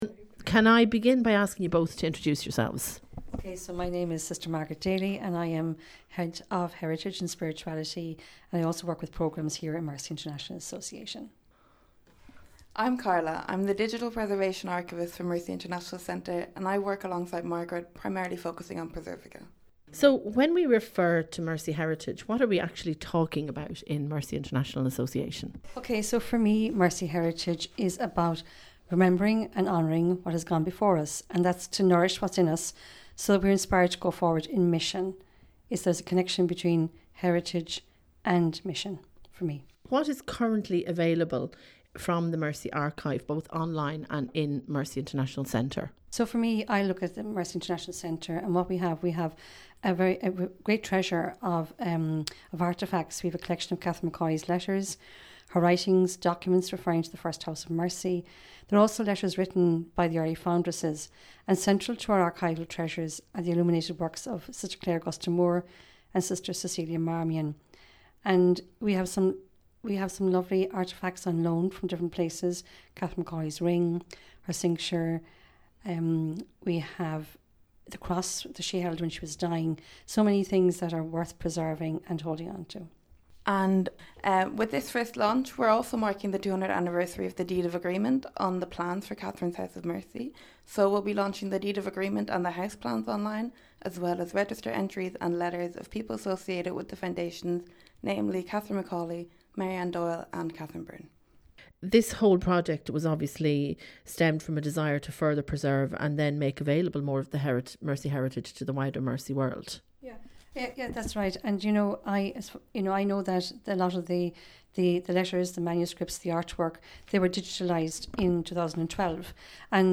Interview on Mercy Heritage online archive